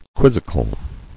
[quiz·zi·cal]